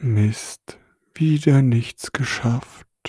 sleepy.wav